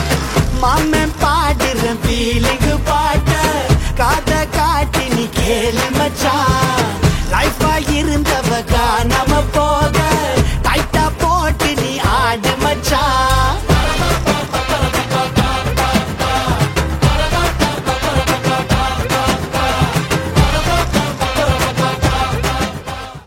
Ringtone File
peppy and emotional track